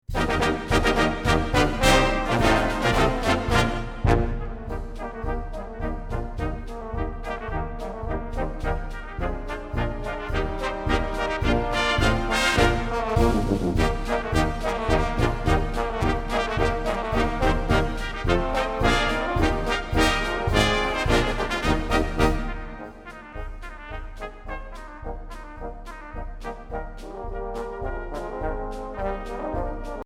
Tentet - Giant Brass